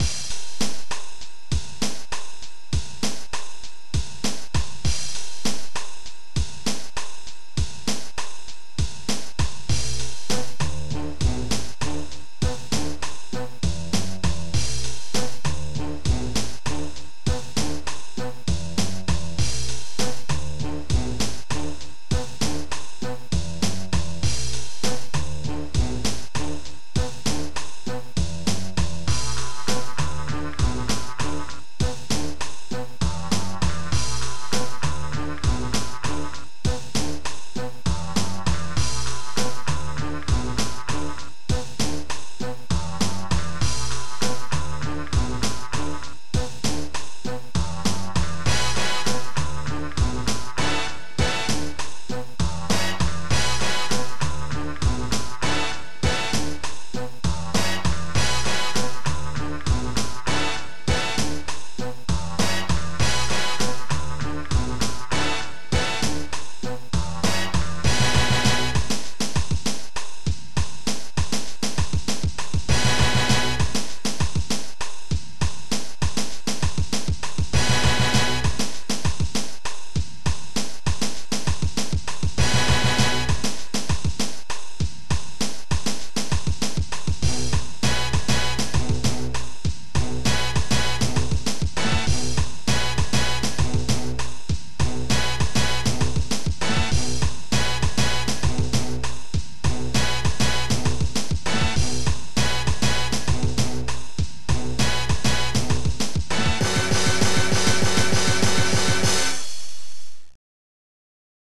Synth
OctaMED Module